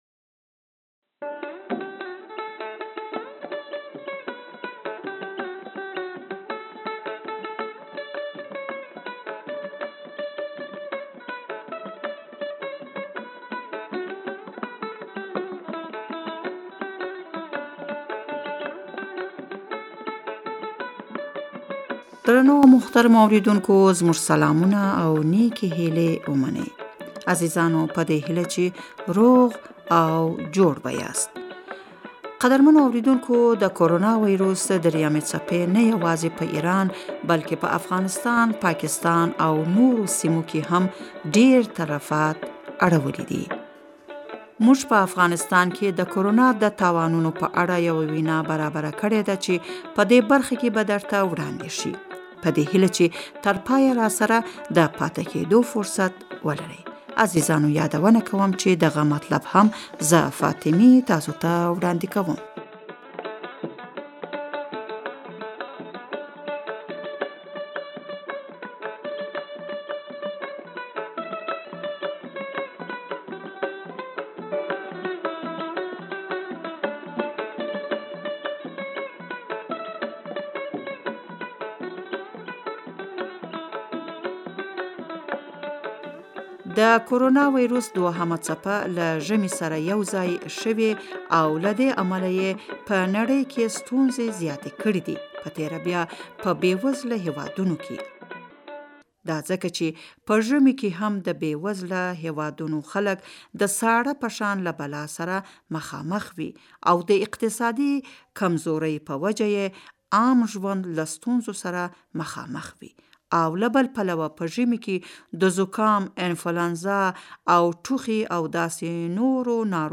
افغانستان کښې د کورونا ویروس د خپریدا په هکله وینا واورئ